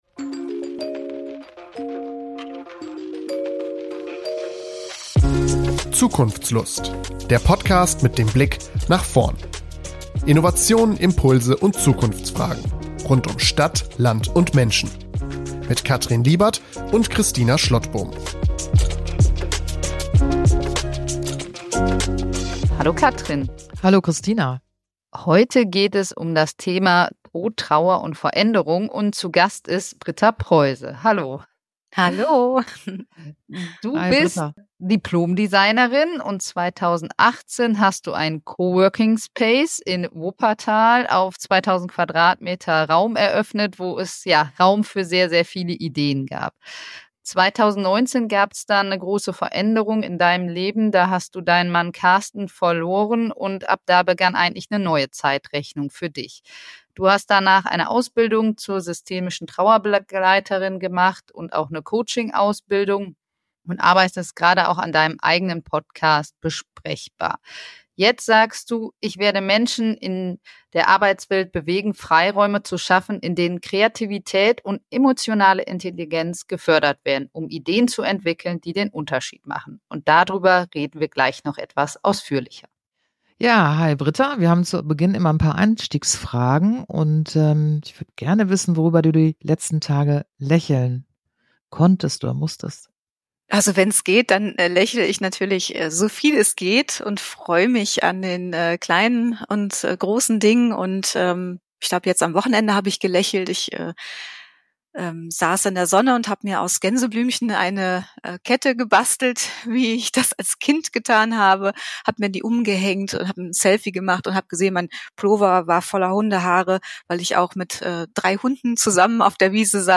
Es war ein sehr bewegendes Gespräch für uns alle drei, hört rein.